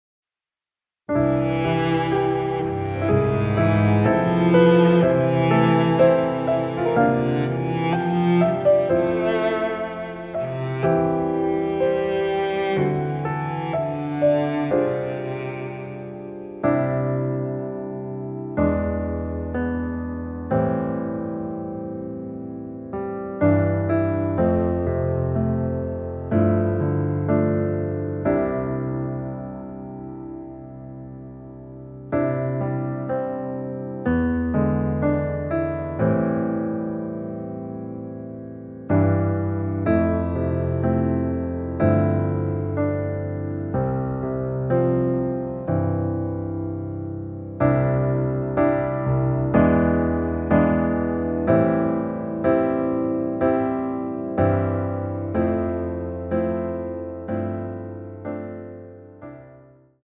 [공식 음원 MR]
앞부분30초, 뒷부분30초씩 편집해서 올려 드리고 있습니다.
중간에 음이 끈어지고 다시 나오는 이유는